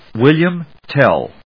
/‐tél(米国英語)/